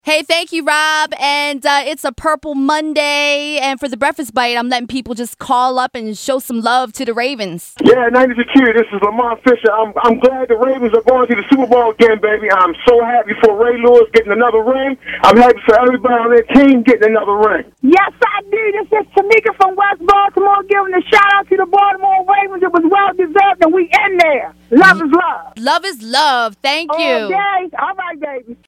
AND Click To Hear More PURPLE LOVE from CALLERS